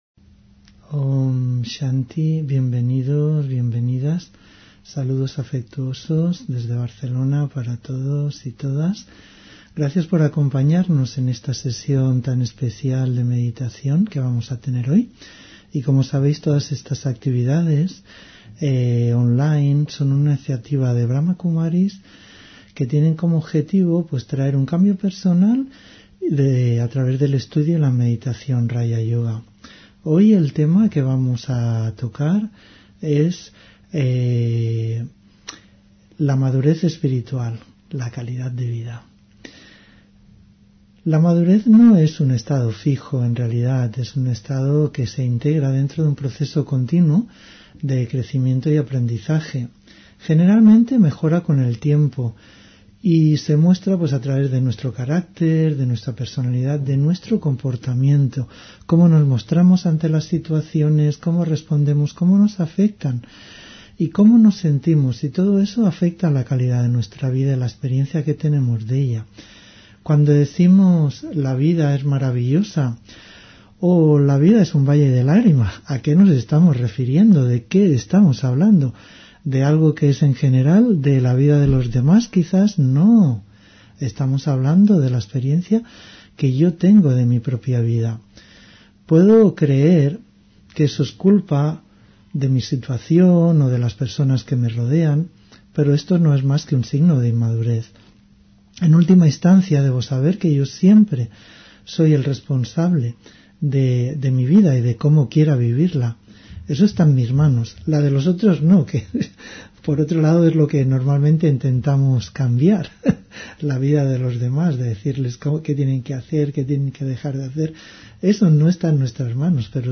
Meditación y conferencia: Madurez espiritual, calidad de vida (24 Junio 2024)